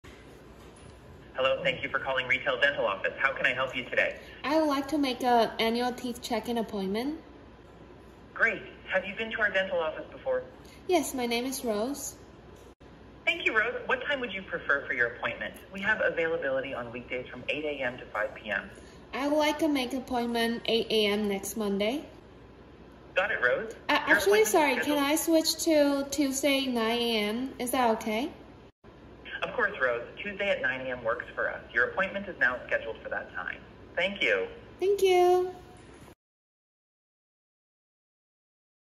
Ukázka AI Voice Agenta
Poslechněte si, jak náš AI agent vyřizuje hovor:
AI_voice_showcase.mp3